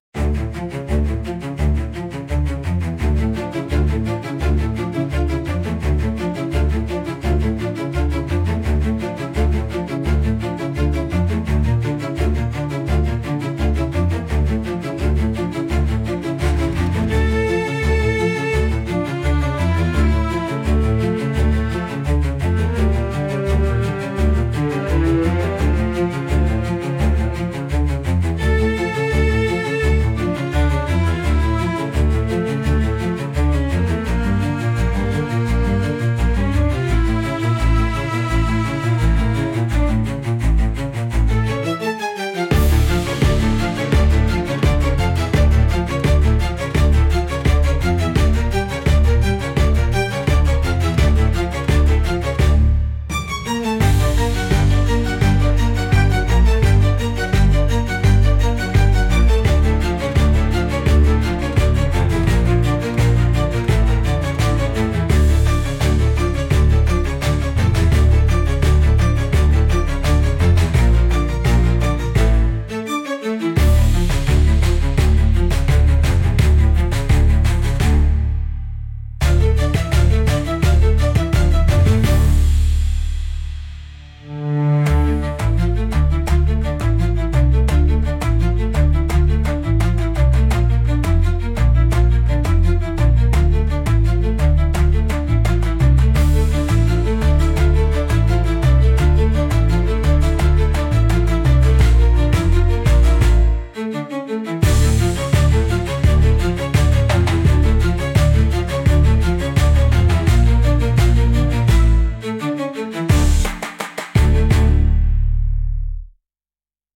it evokes a deep, unbreakable intensity.
クールで鋭いサウンドの中に、少しミステリアスな雰囲気が漂う、大人の風格漂う一曲。